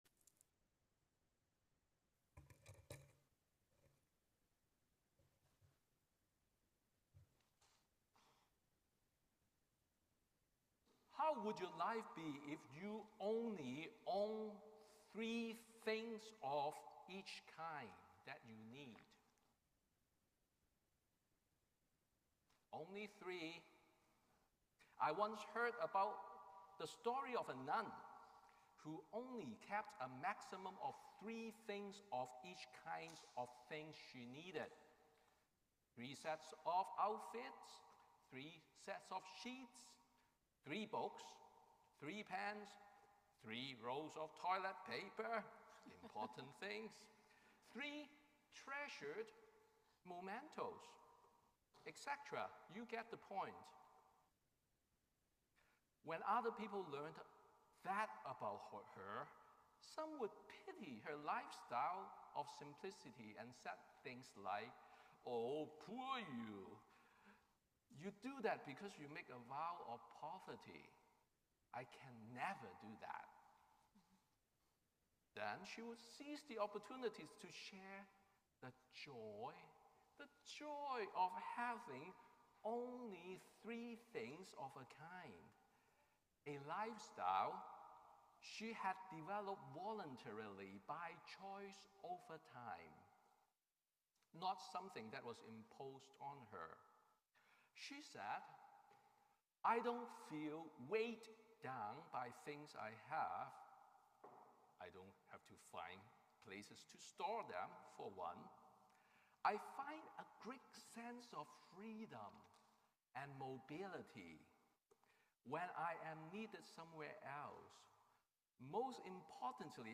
Sermon on the Eighth Sunday after Pentecost